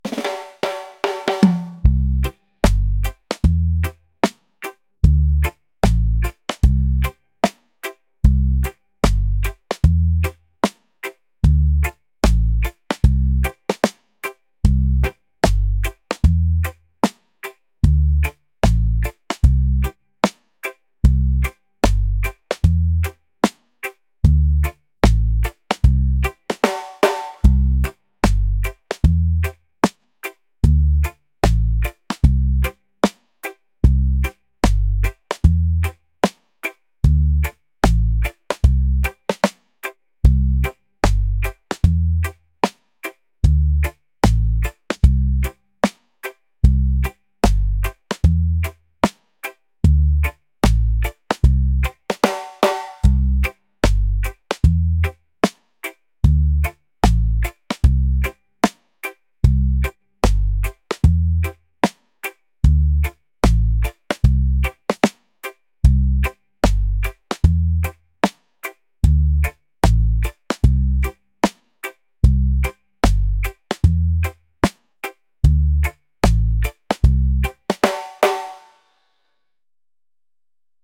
island | laid-back | reggae